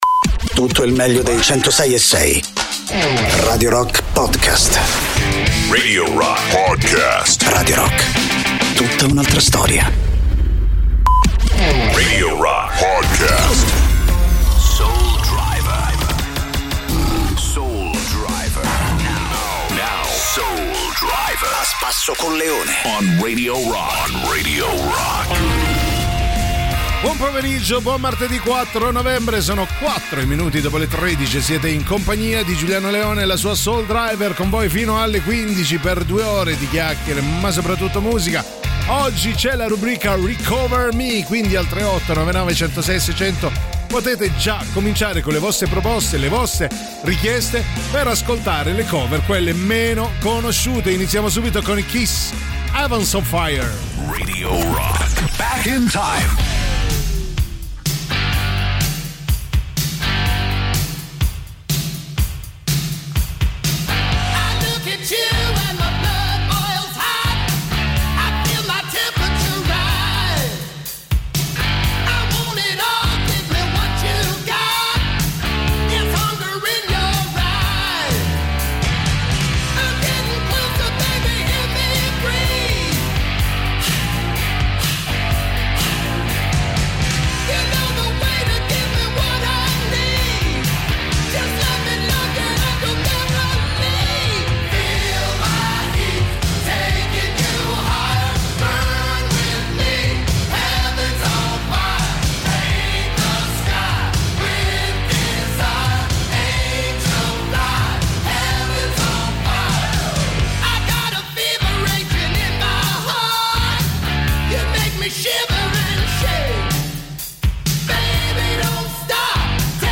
in diretta dal lunedì al venerdì, dalle 13 alle 15, con “Soul Driver” sui 106.6 di Radio Rock.